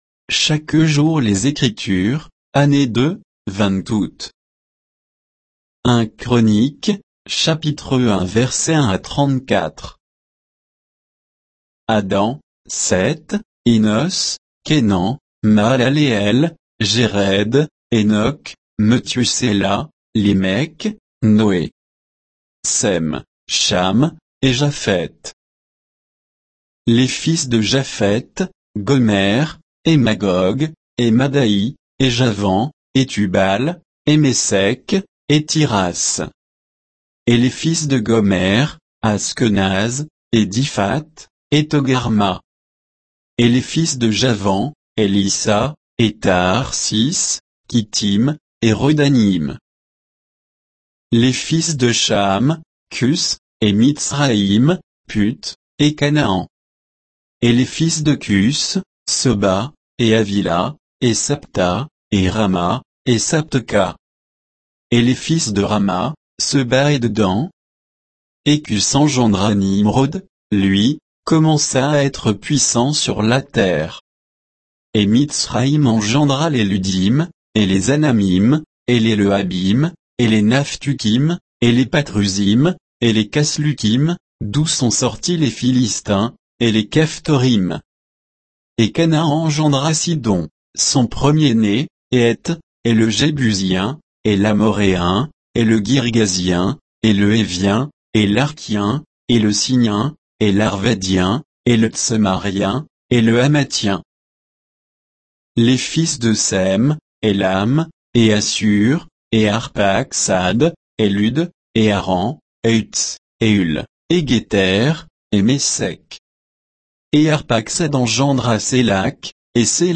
Méditation quoditienne de Chaque jour les Écritures sur 1 Chroniques 1